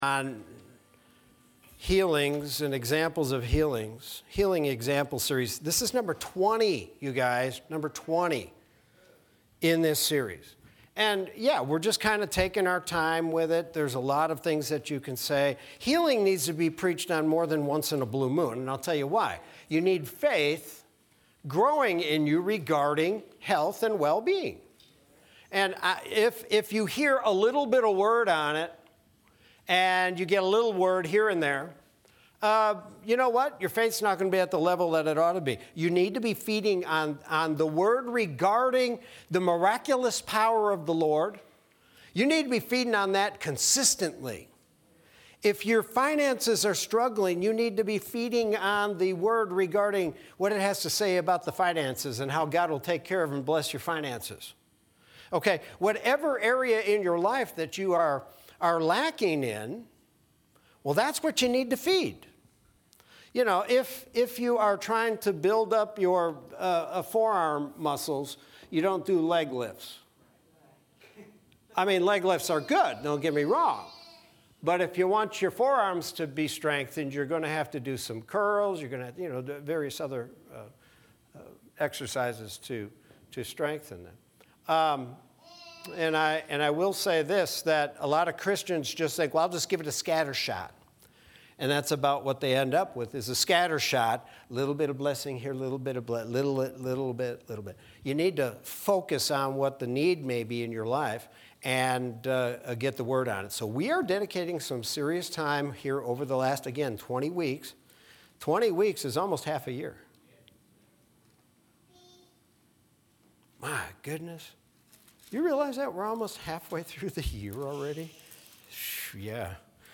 Sermon from Wednesday, June 23rd, 2021.